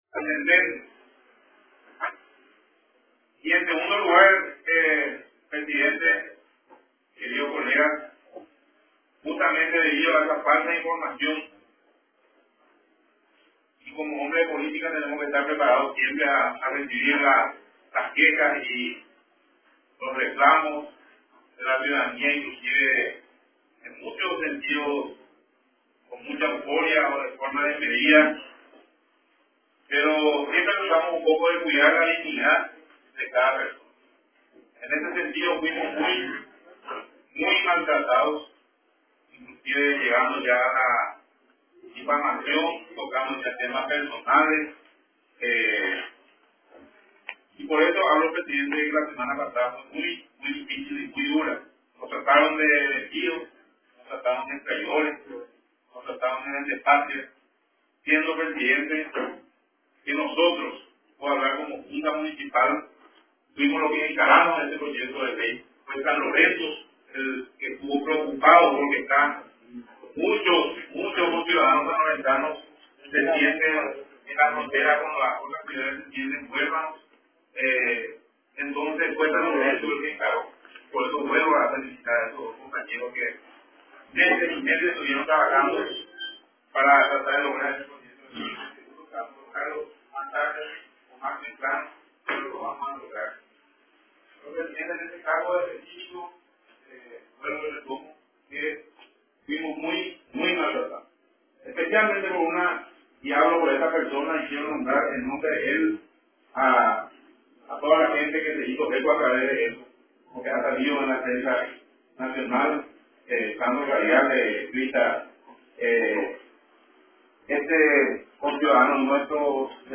durante la sesión ordinaria de la Junta Municipal de San Lorenzo